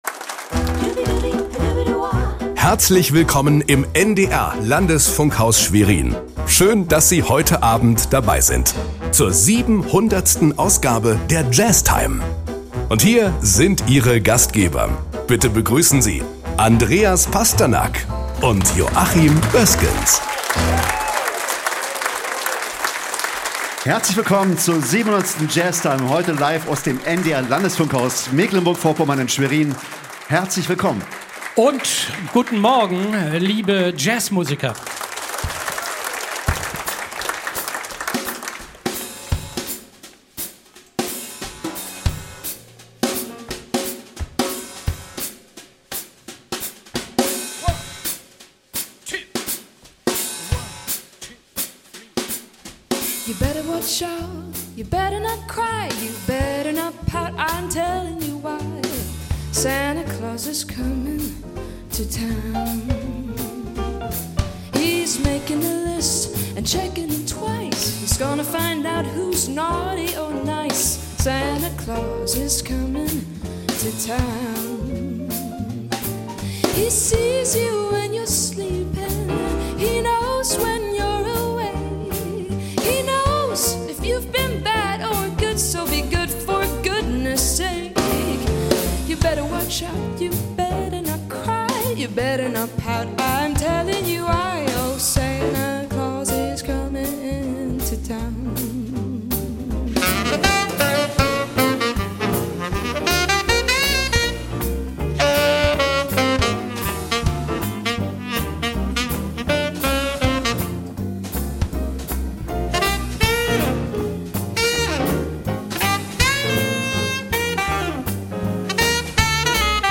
Weihnachtsklassiker
(Gesang)
(Saxofon)
(Piano)
(Drums)
(Gitarre)
(Bass)